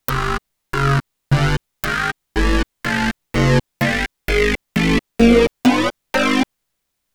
intermodulation_scale.wav